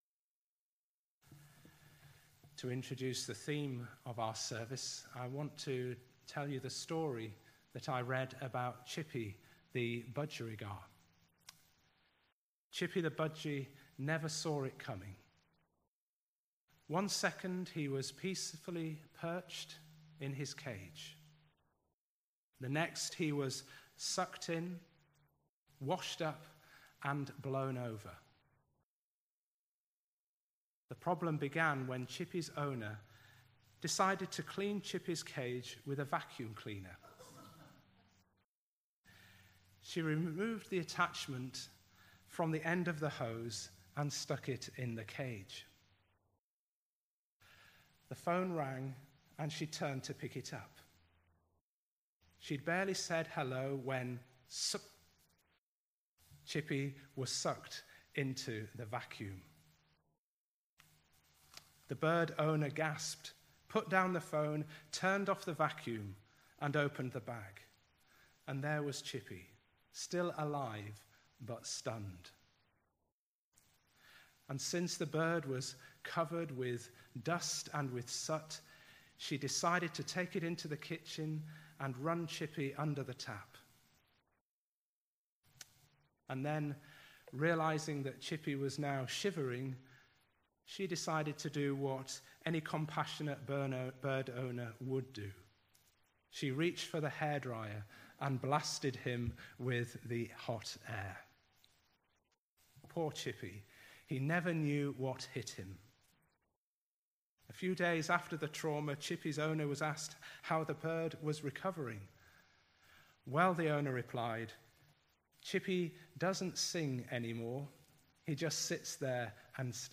This service included Holy Communion.
Service Type: Sunday Evening